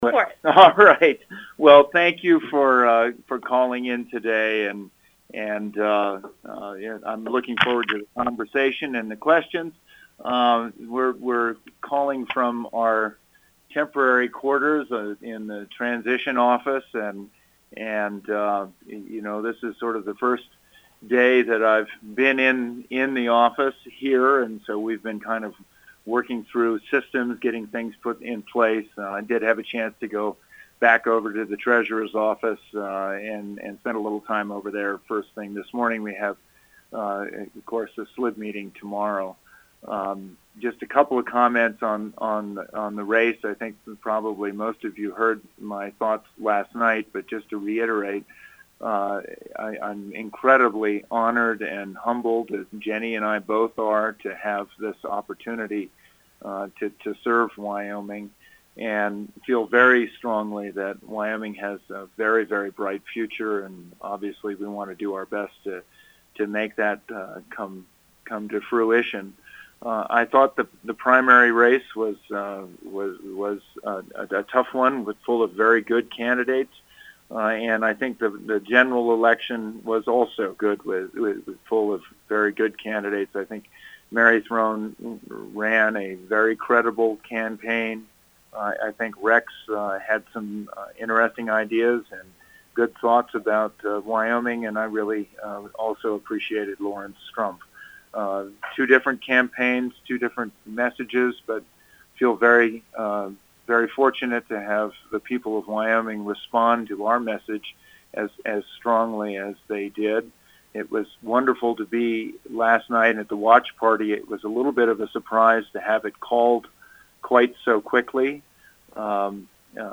MARK GORDON INTERVIEW – Big Horn Mountain Radio Network | Wyoming